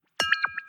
ting-dualchannel44.1-expected.wav